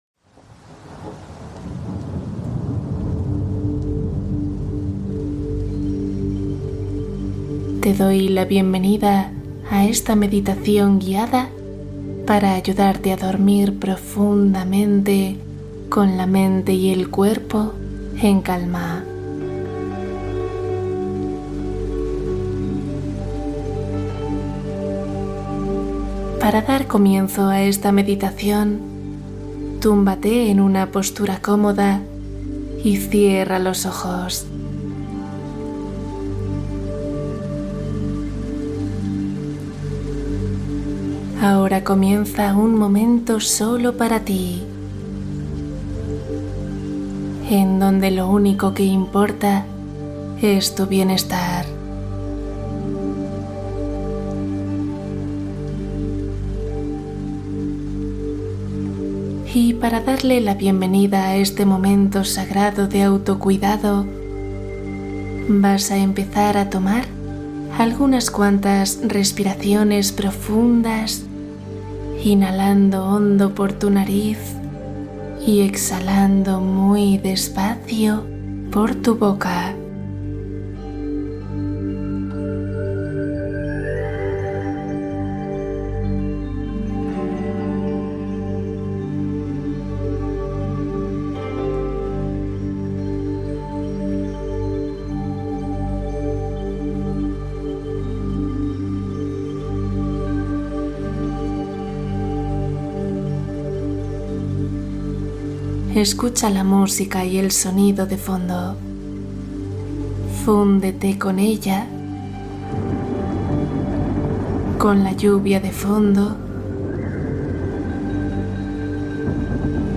Soltar emociones al final del día: meditación de descarga emocional